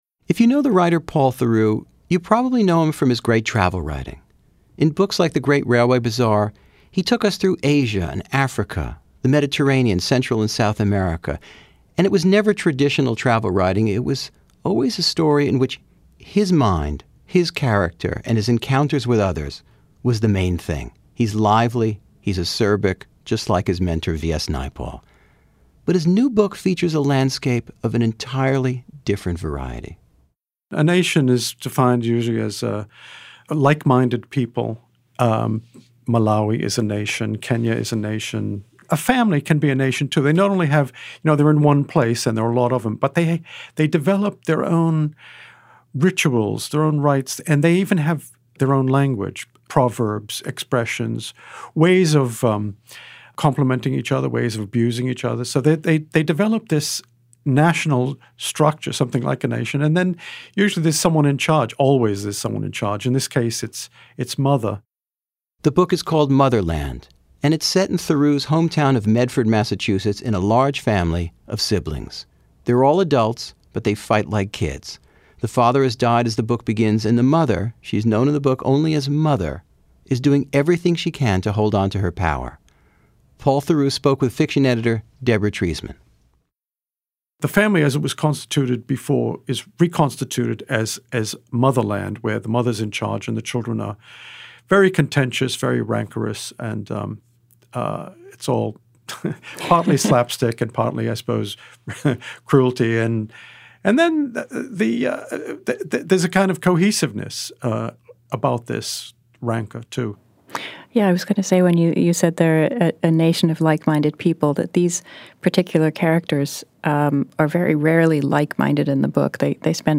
Now an interview with The New Yorker Radio Hour has banished 20 years of presumption about what I thought Paul Theroux might sound like.
His spoken voice is less certain, more affected, a cross of British-sounding intonations and patrician New England syllables. ‘Writer’ is ‘Writa’; ‘Awarded” is ‘Awaurded’; ‘Father’ is ‘Fawtha’; ‘Mocking’ is ‘Mawking’. I hear Bernie Sanders in it; Theroux can be piping, short of reedy, other times gravelly, but never sonorous.